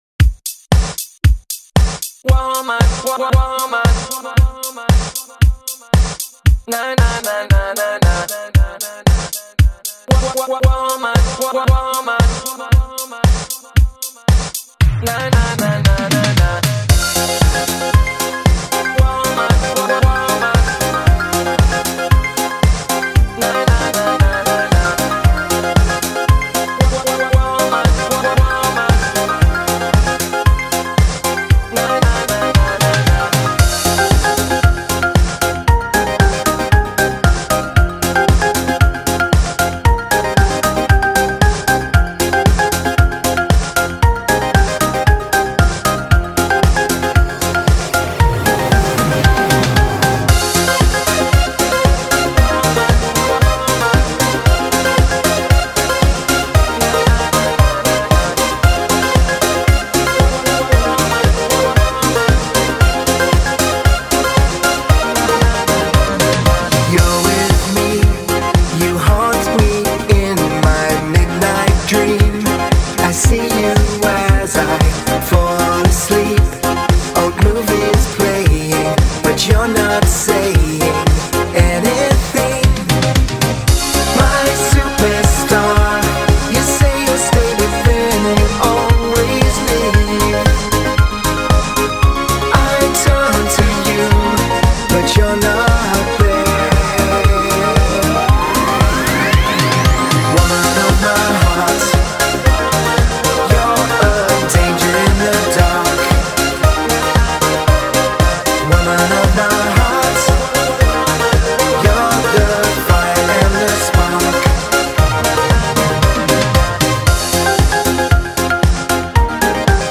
барабаны, клавиши и т.д.